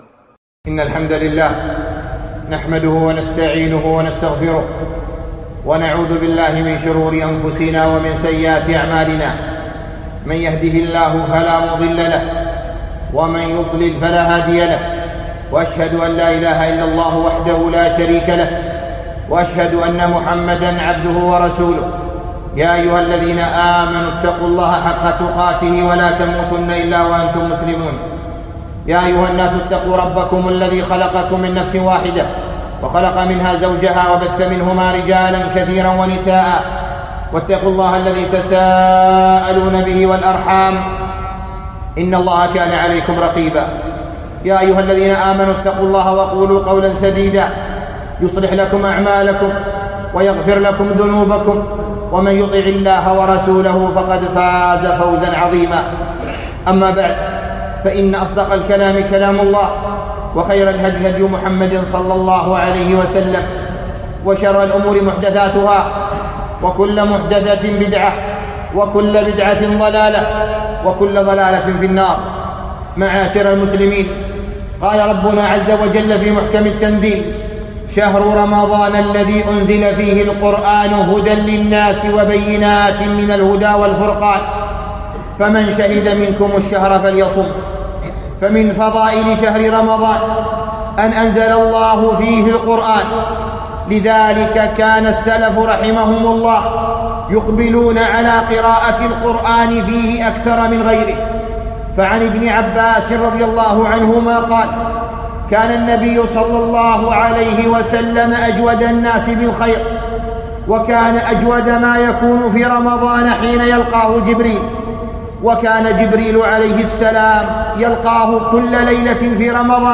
خطب - موقع دروس الإمارات